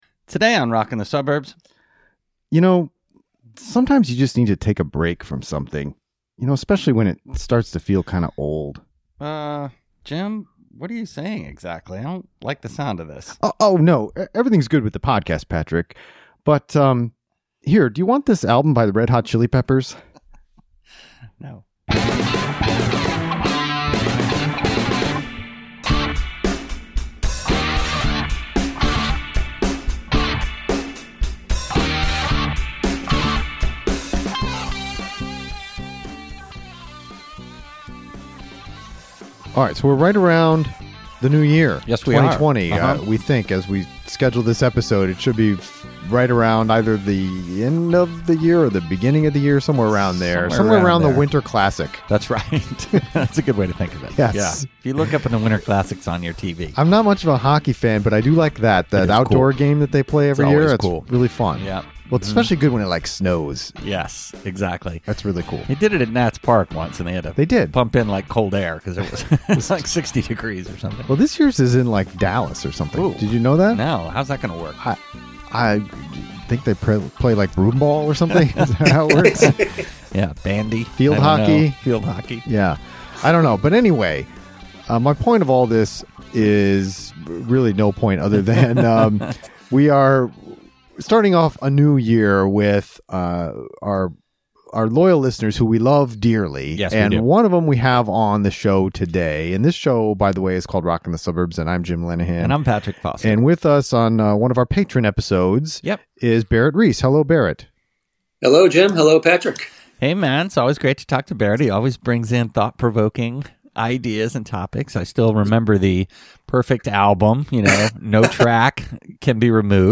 We connect via FaceTime and discuss bands that we have broken up with - meaning we used to have a strong relationship with them, but feelings have diminished over time.